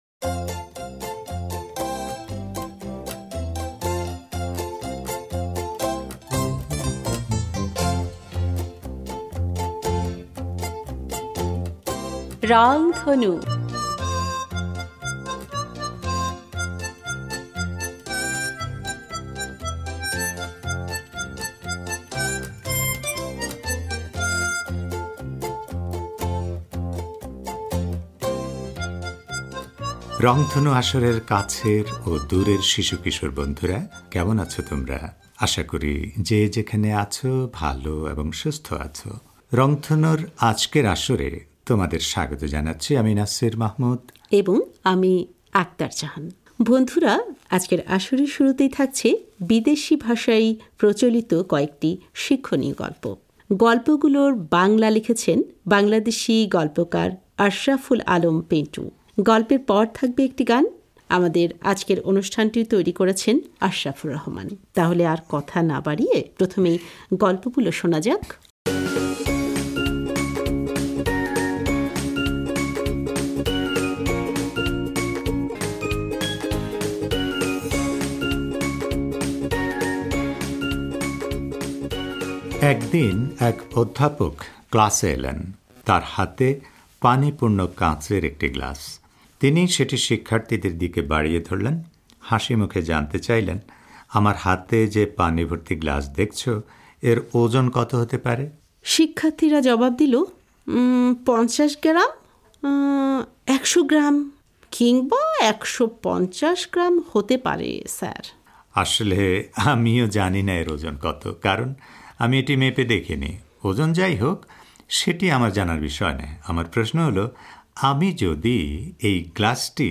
গল্পের পর থাকবে একটি গান।